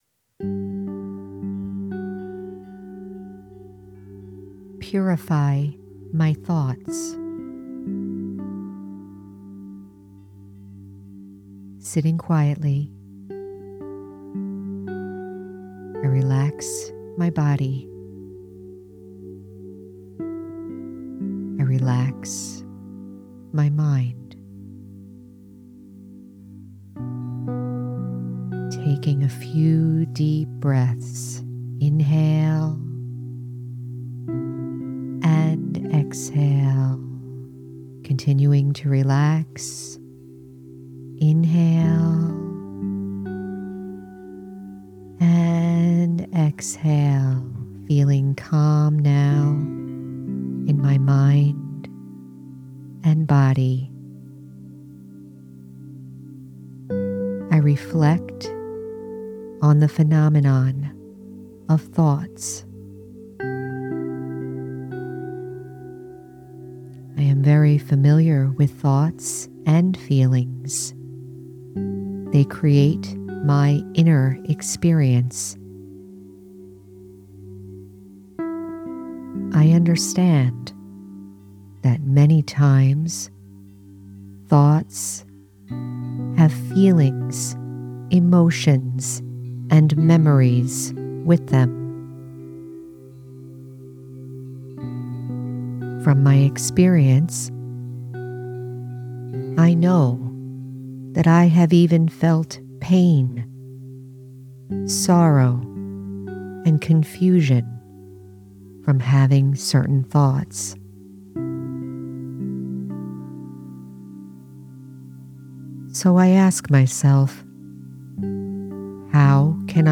Purify my Thoughts- Guided Meditation- The Spiritual American- Episode 168